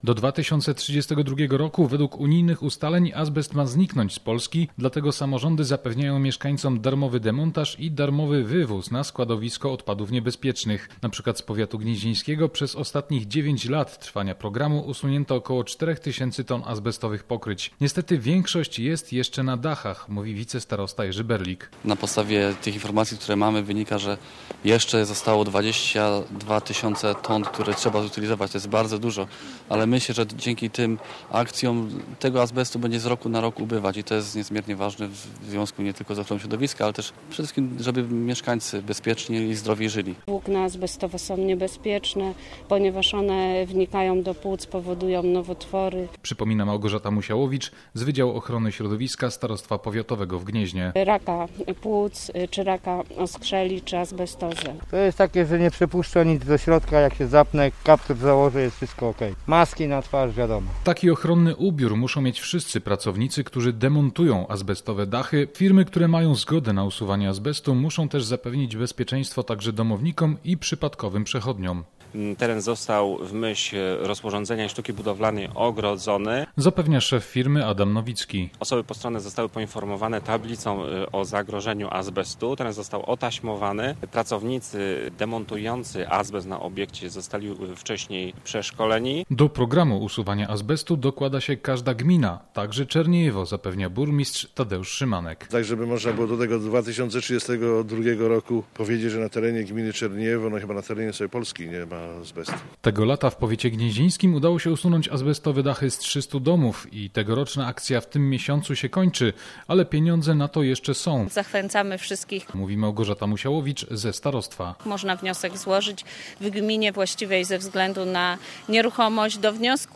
Takiej operacji w Żydowie koło Gniezna przyglądał się nasz reporter: